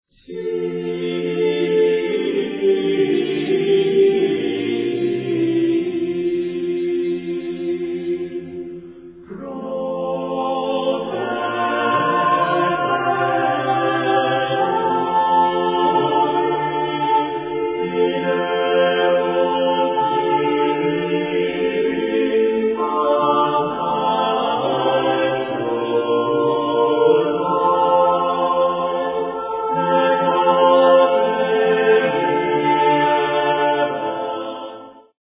Renaissance Polyphony